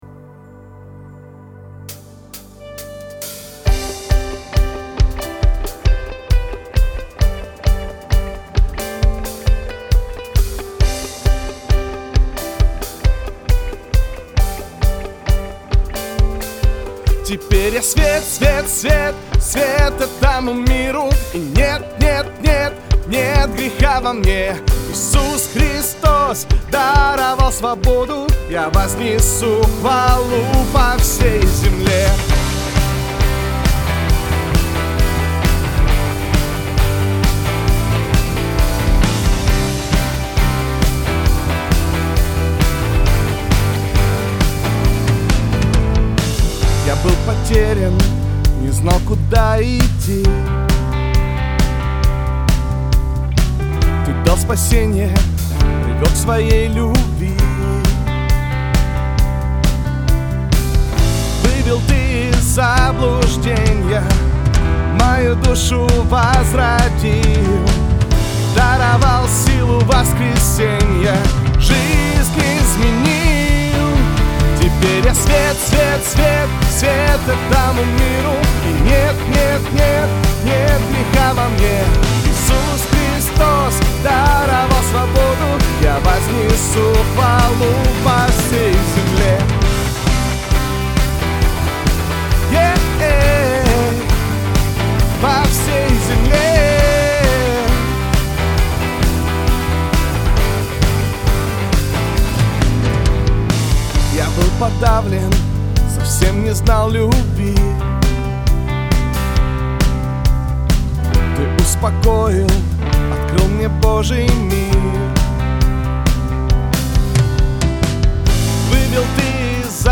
913 просмотров 608 прослушиваний 23 скачивания BPM: 134